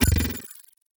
Hi Tech Alert 2.wav